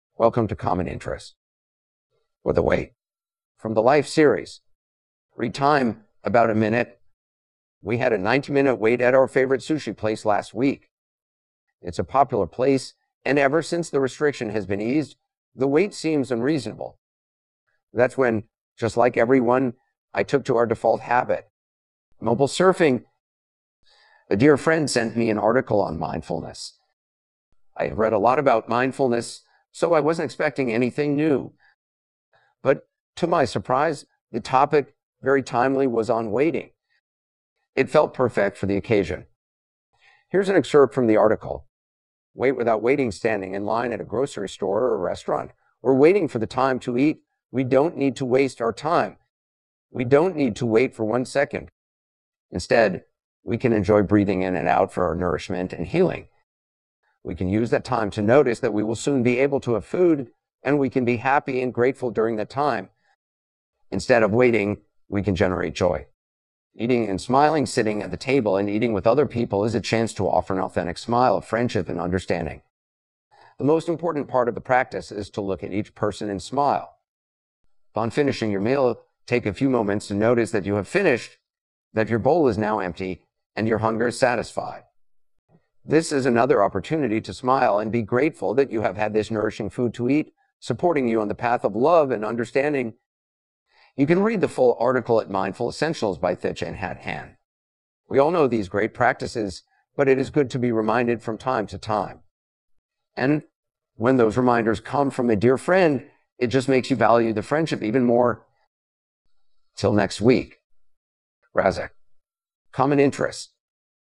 For those who prefer listening vs reading, I have an audio version of the blog.